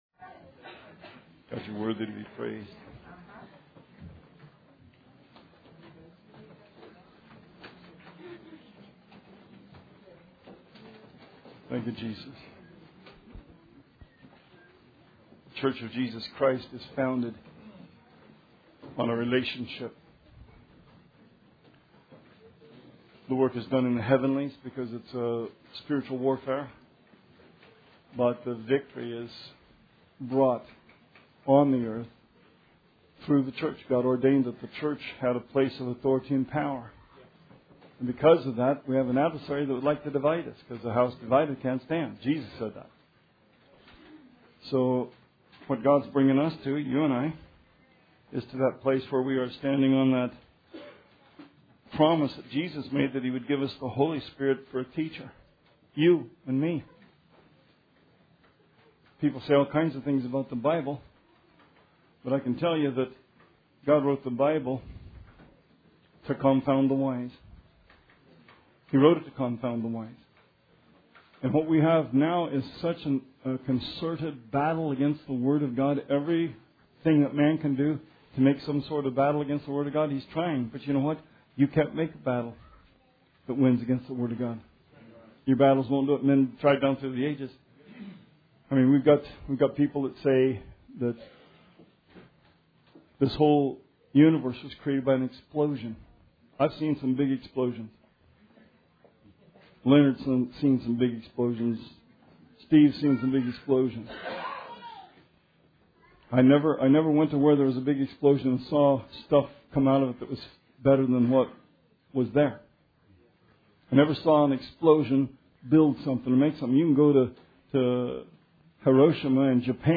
Sermon 6/5/12